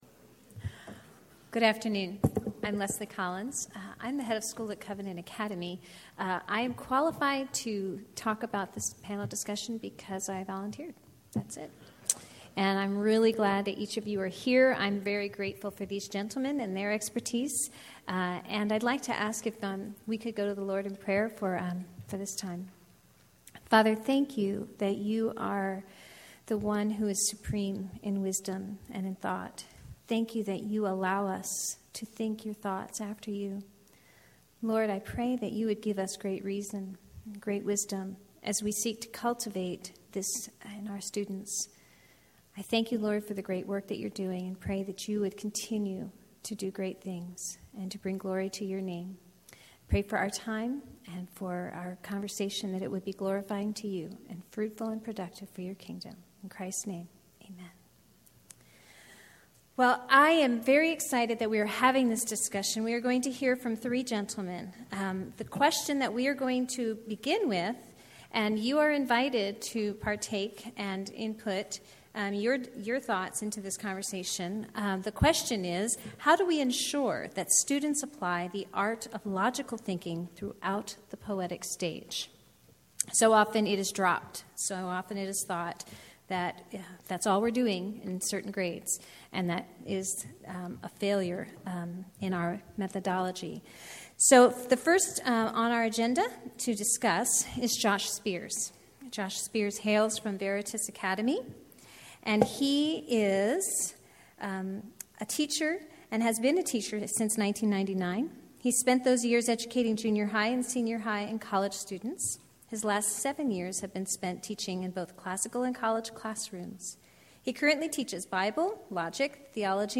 2012 Workshop Talk | 1:04:00 | 7-12, Logic
Each panelist will make opening remarks. Following these remarks, the panel will answer questions from the audience.
How Do We Ensure that Students Apply the Art of Logical Thinking Throughout the Poetic Stage Panel Discussion.mp3